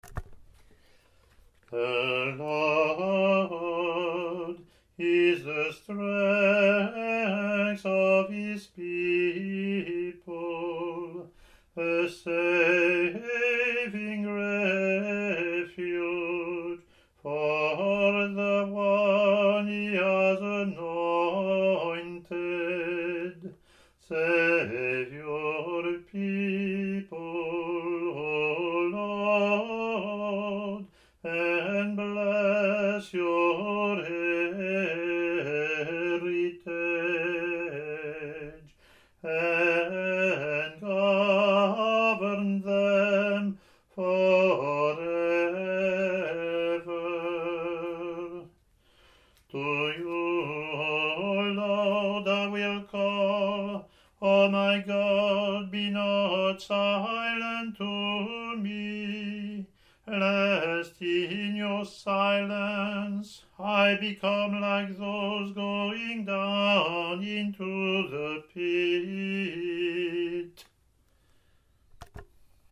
Introit in Solfeggio:
English antiphon – English verseLatin antiphon and verse)